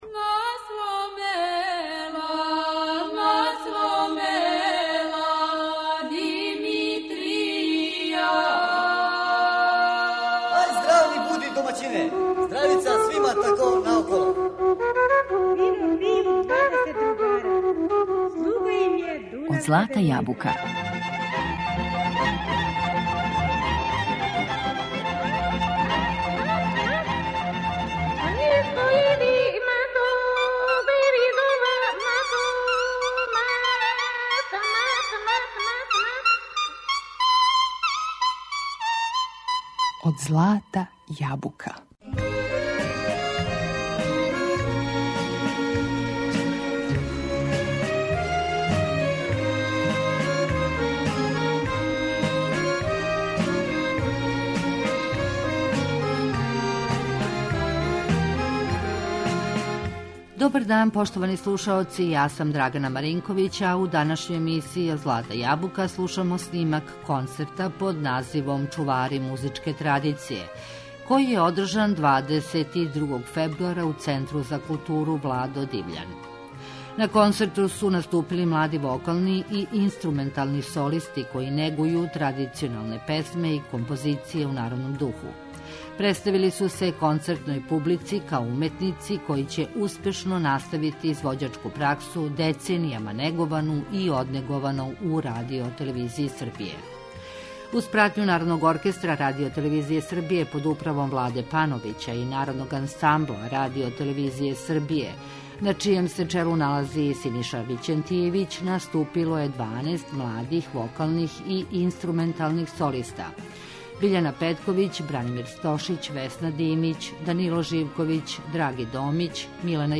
У данашњој емисији 'Од злата јабука', слушамо снимак концерта под називом 'Чувари традиције', који је одржан 22. фебруара у Центру за културу 'Владо Дивљан'.
На концерту су наступили млади вокални и инструментални солисти који негују традиционалне песме и композиције у народном духу.